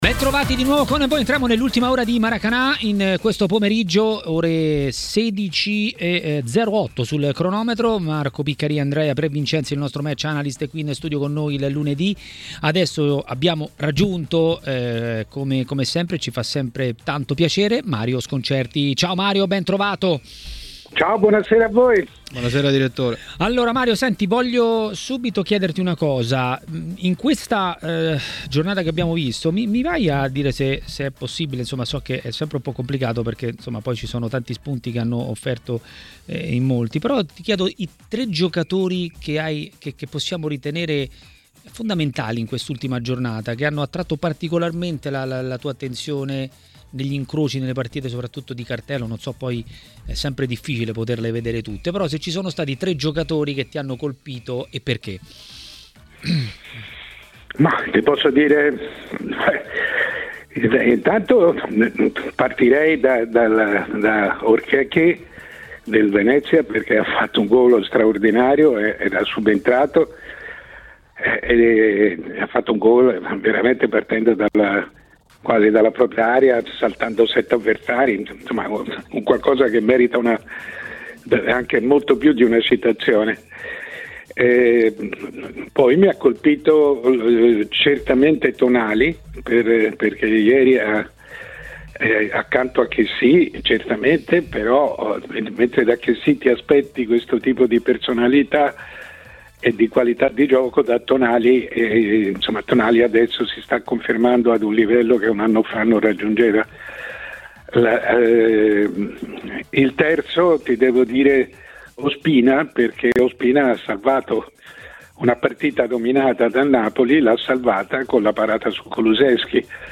A commentare la giornata di Serie A a TMW Radio, durante Maracanà, è stato il direttore Mario Sconcerti.